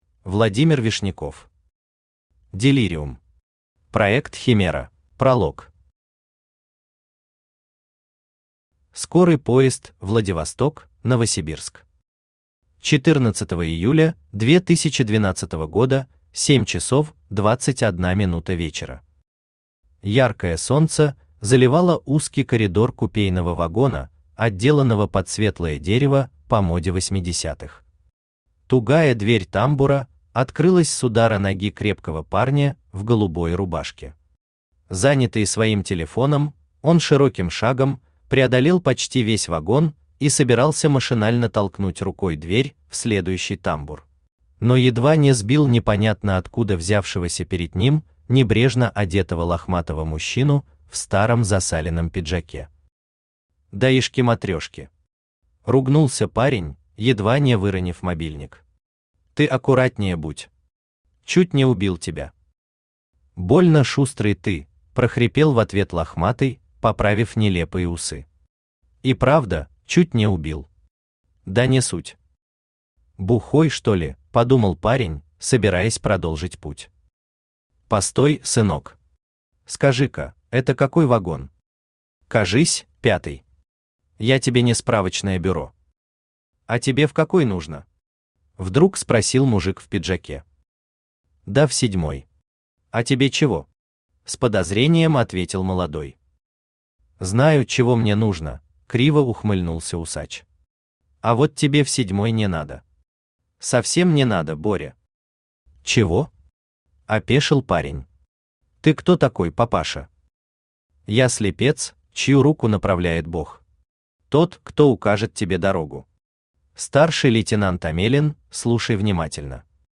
Проект «Химера» Автор Владимир Вишняков Читает аудиокнигу Авточтец ЛитРес.